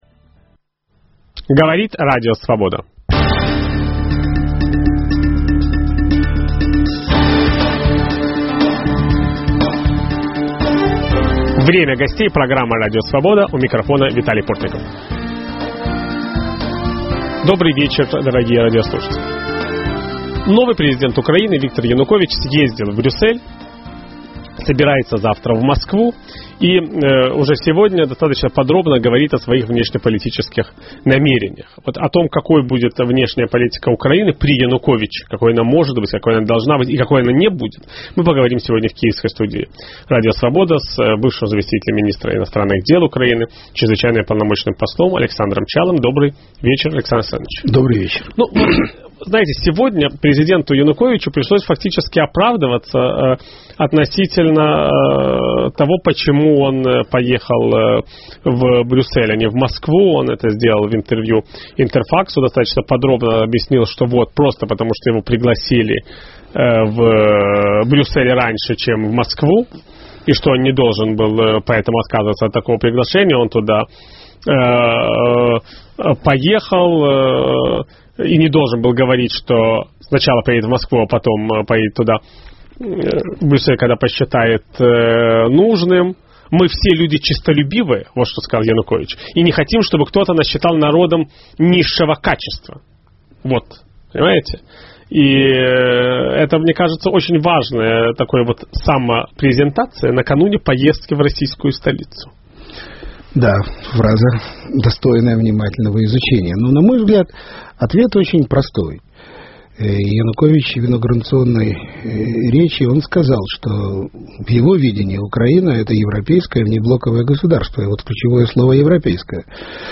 Какой будет внешняя политика нового президента Украины? Виталий Портников беседует с бывшим заместителем министра иностранных дел страны Александром Чалым.